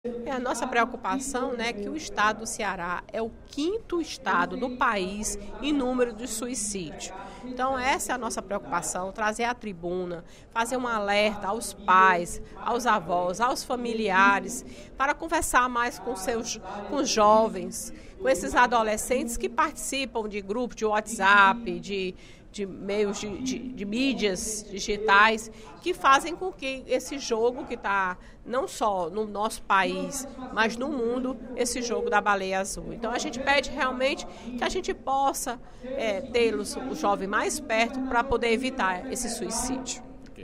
A deputada Fernanda Pessoa (PR) alertou, durante o primeiro expediente da sessão plenária da Assembleia Legislativa desta terça-feira (25/04), sobre a elevação do número casos de suicídio de jovens no Brasil e no mundo. Segundo ela, o aumento de casos vem sendo estimulado por um jogo denominado Baleia Azul.